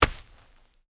Heavy (Running)  Dirt footsteps 2.wav